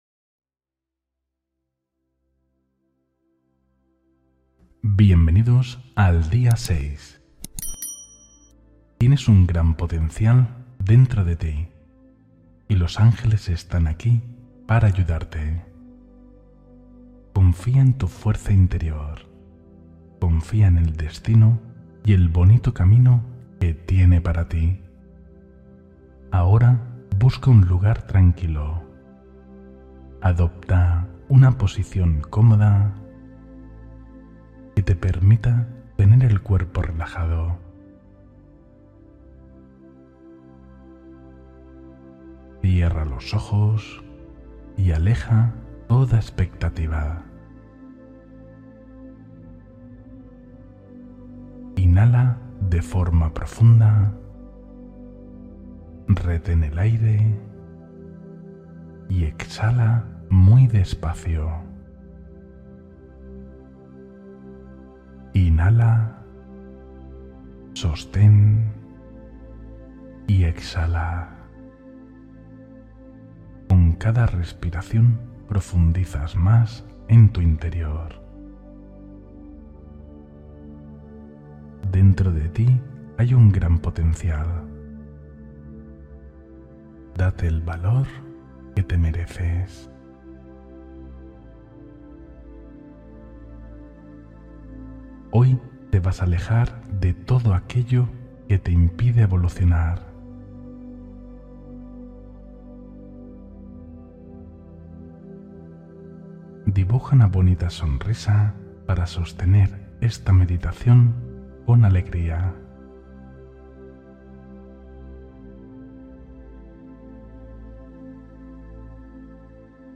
Fortaleza Interior Guiada: Meditación con Viaje Simbólico